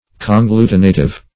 Conglutinative \Con*glu"ti*na"tive\, a.